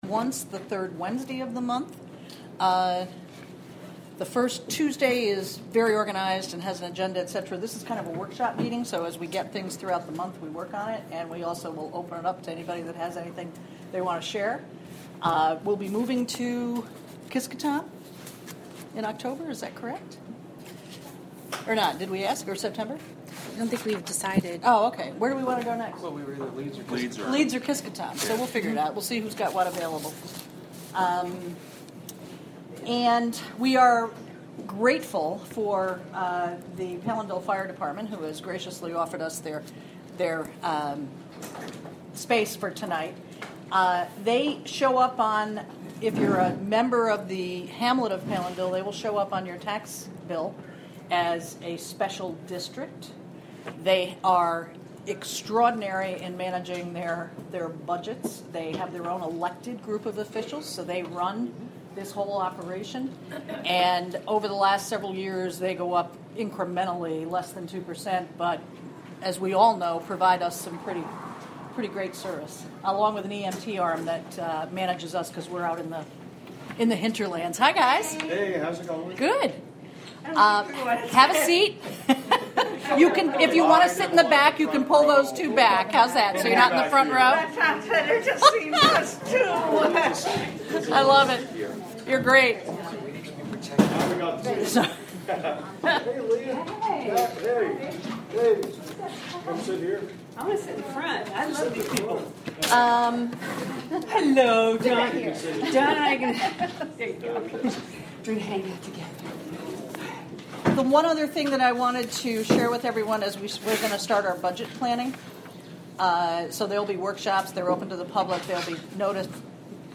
Monthly Town Board Committee meeting.
Recorded from a live webstream created by the Town of Catskill through the Wave Farm Radio app.
This month, the workshop meeting was held at the Palenville firehouse located at 717 NY Route 32A in Palenville.